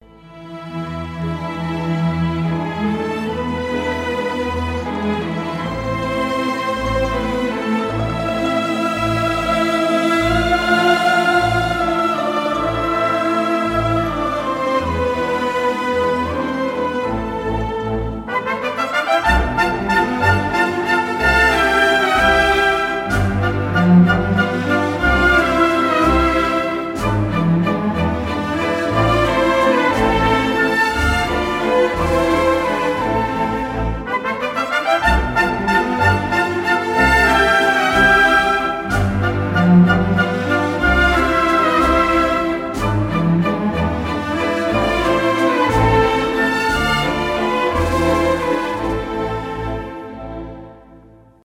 инструментальные , без слов
классические , вальс , оркестр